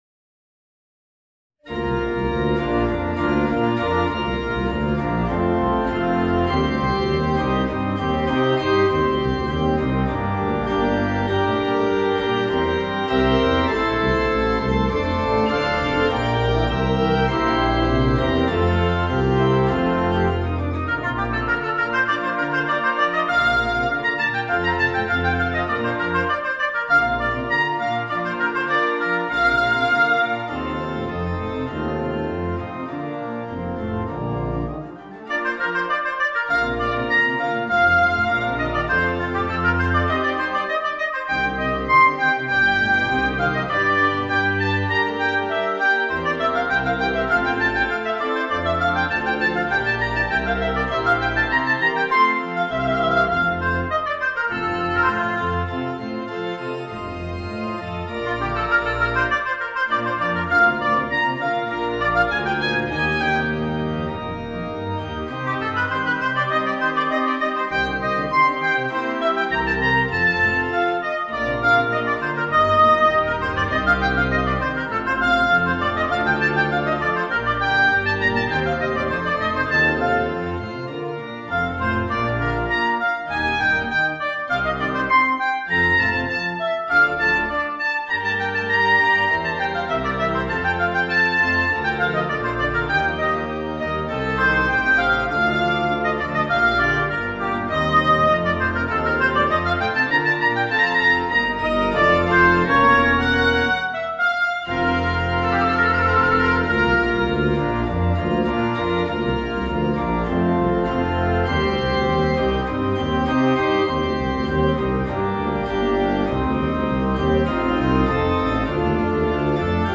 Voicing: Oboe and Organ